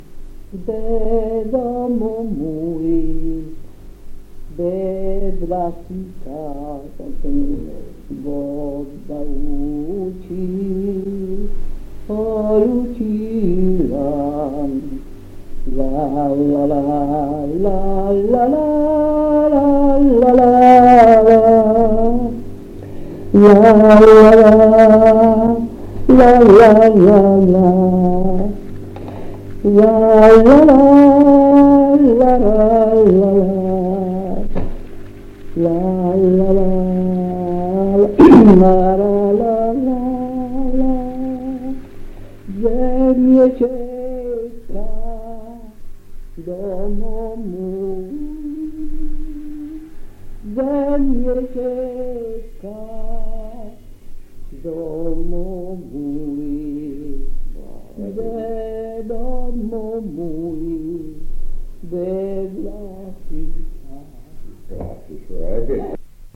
Post 1975. 1 bobina di nastro magnetico.